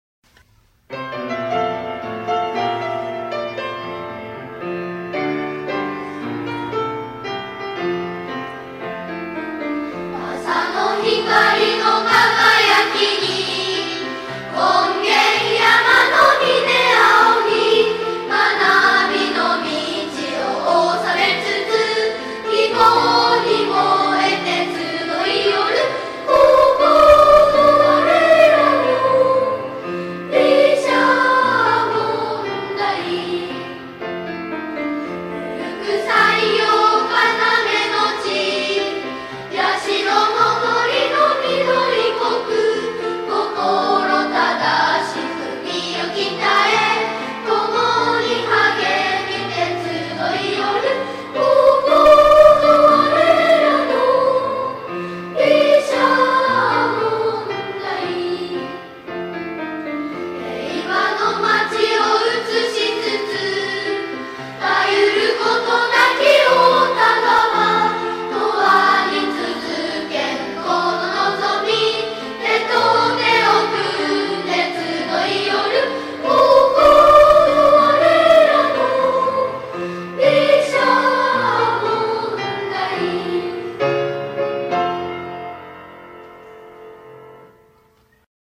毘沙門台小学校　校歌